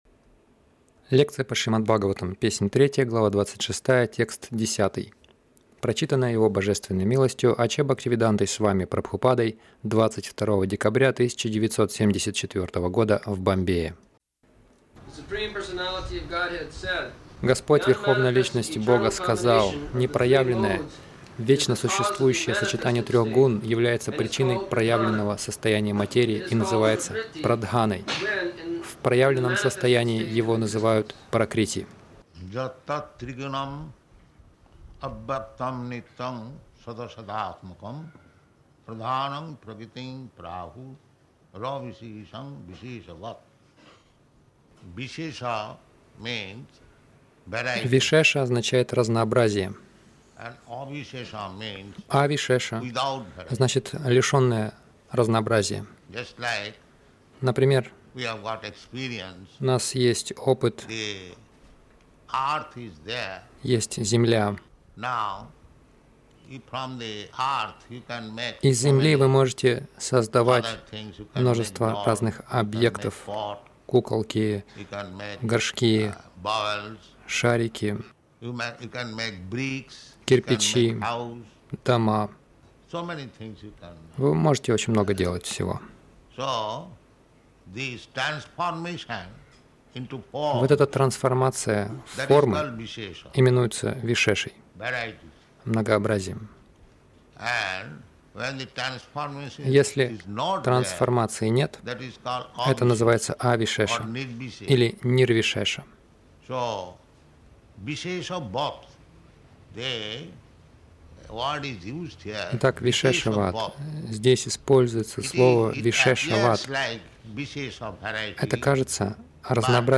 Милость Прабхупады Аудиолекции и книги 22.12.1974 Шримад Бхагаватам | Бомбей ШБ 03.26.10 — Мы — частички Бога Загрузка...